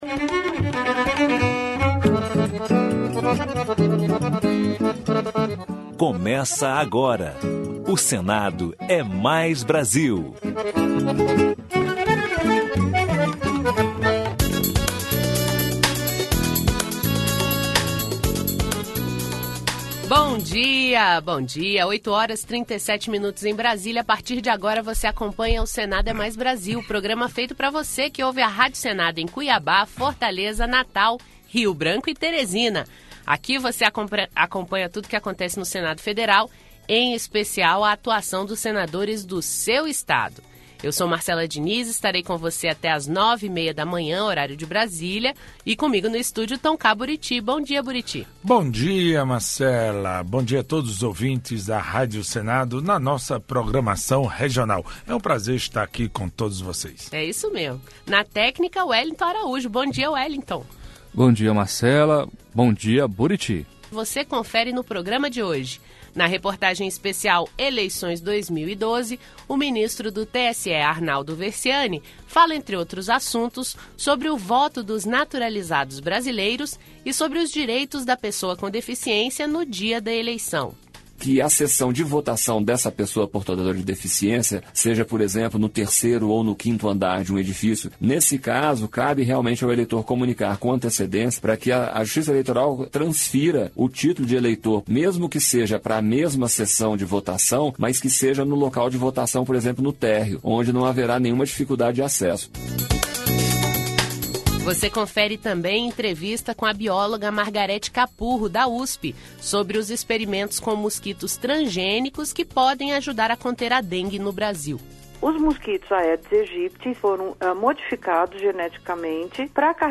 Entrevista Especial